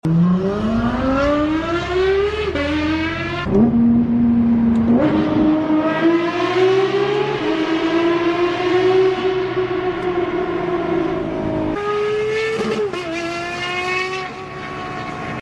Sound F1 gtr3 rs escape